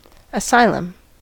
asylum: Wikimedia Commons US English Pronunciations
En-us-asylum.WAV